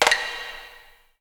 A#3 STICK0DR.wav